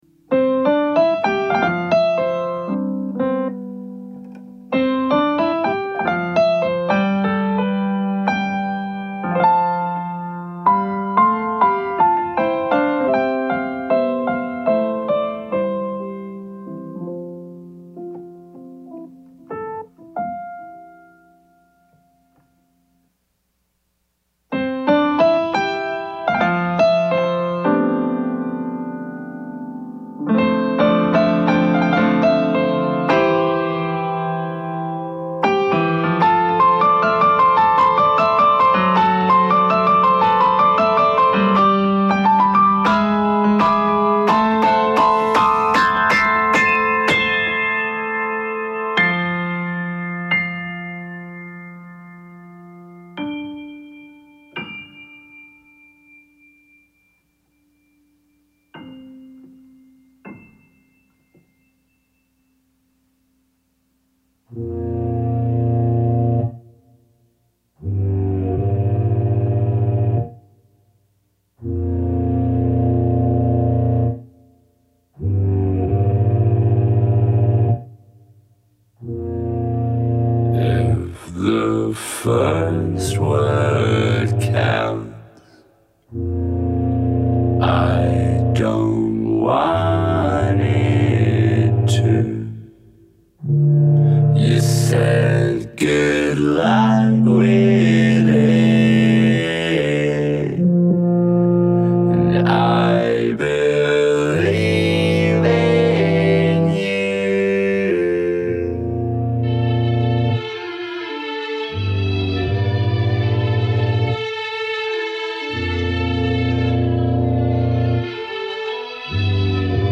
If we feel movies so much, how much of the film's effect is due to music? That's one question I want to explore in a new radio series produced live weekly.